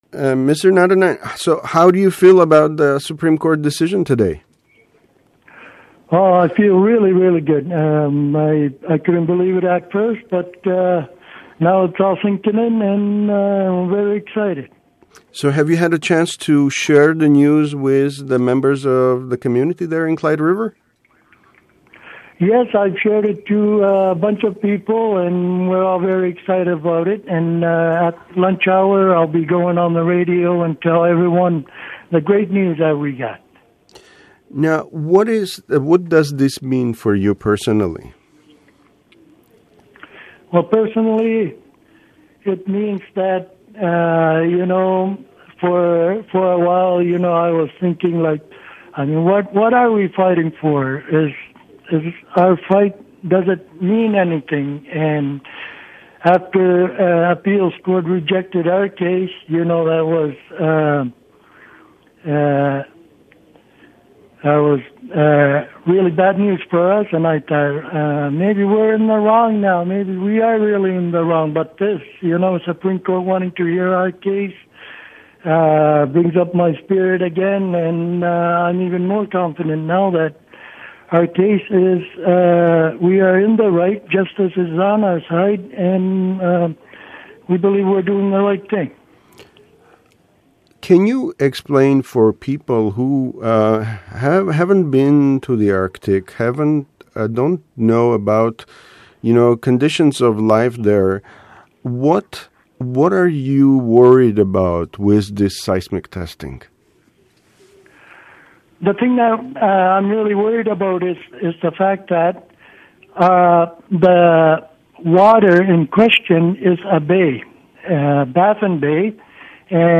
“The Supreme Court wanting to hear our case brings up my spirit again and I’m even more confident now that we are in the right, justice is on our side and we believe we’re doing the right thing,” Natanine said speaking on the phone from his house in Clyde River Thursday morning.